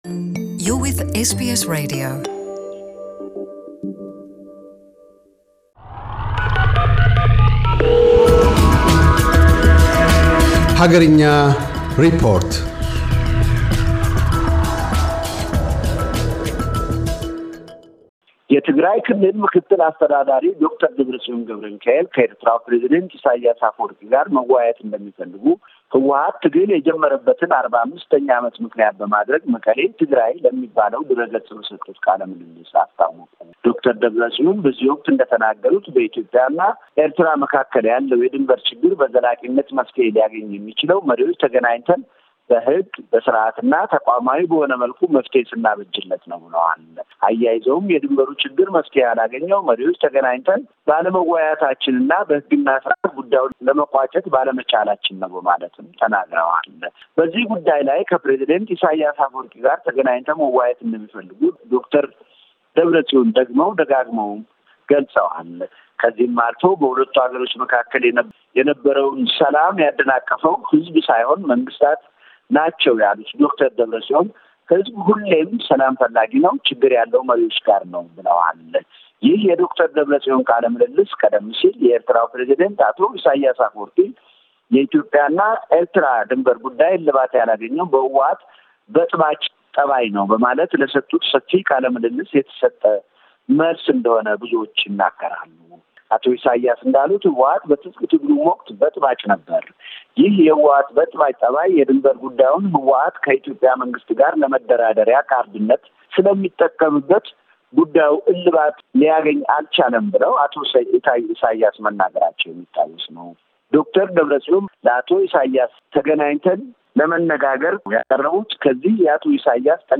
አገርኛ ሪፖርት- ዶ/ር ደብረፅዮን ገብረሚካኤል - የትግራይ ክልል ርዕሰ መስተዳድር፤ ከኤርትራው ፕሬዚደንት ኢሳይያስ አፈወርቂ ጋር ተገናኝተው መነጋገር እንደሚሹ መግለጣቸውን ቀዳሚ ትኩረቱ አድርጓል።